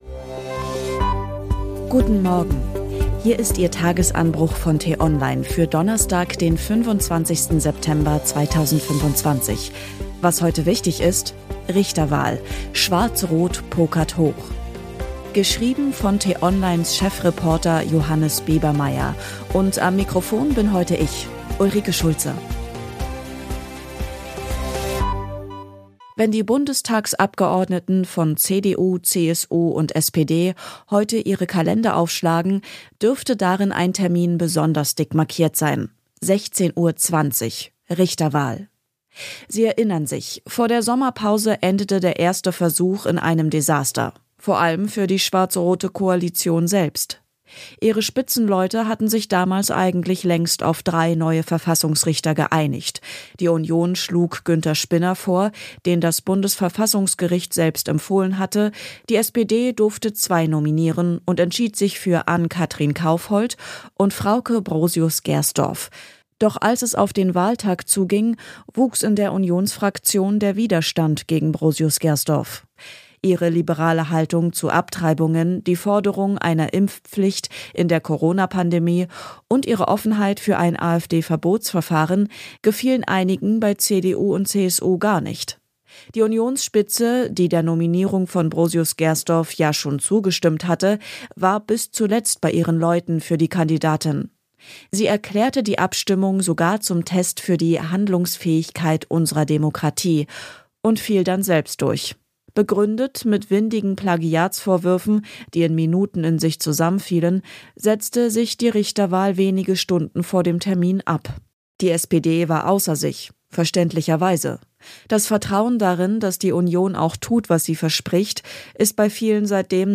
Der Nachrichtenpodcast von t-online zum Start in den Tag.